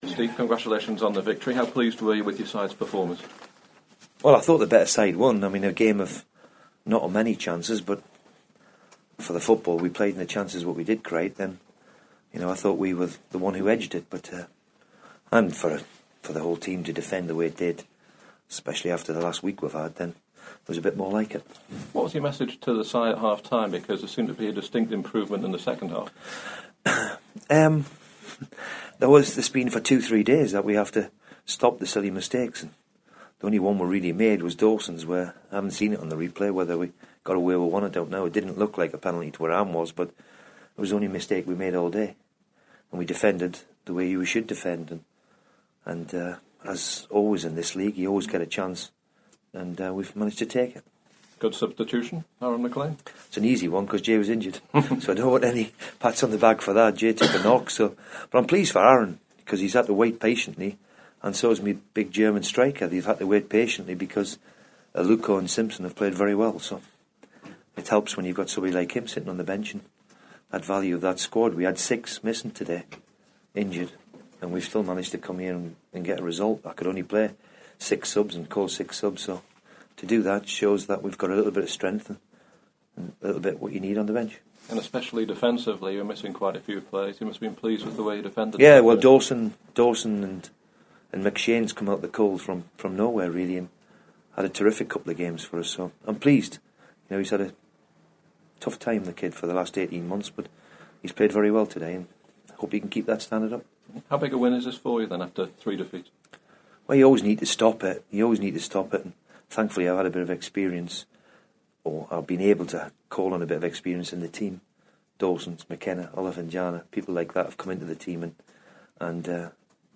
Reaction from Hull City boss Steve Bruce to his side's first win in four games at Sheffield Wednesday on Saturday.